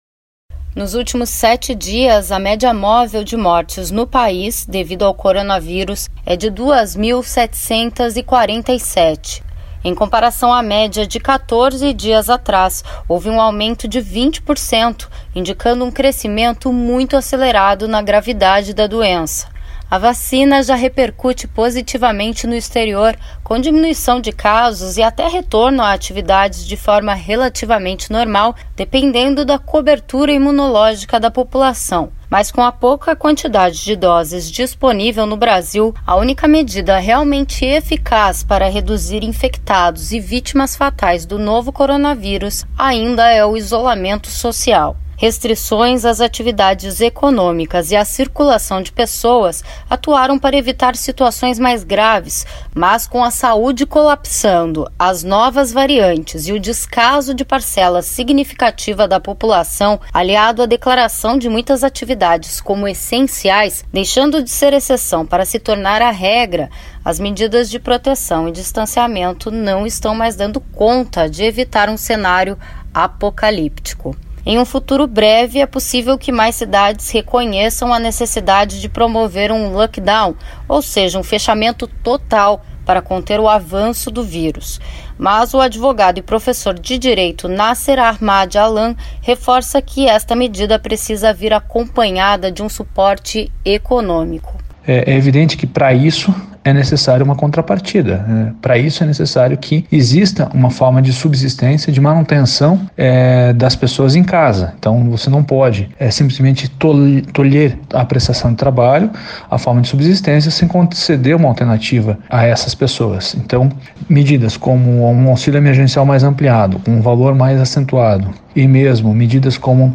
Medidas restritivas precisam vir acompanhadas de suporte financeiro e o valor do novo auxílio emergencial não é suficiente para atender as necessidades nem de trabalhadores nem do mercado financeiro. Um professor de direito avalia as responsabilidades dos gestores públicos no gerenciamento de políticas de atendimento à população durante a pandemia.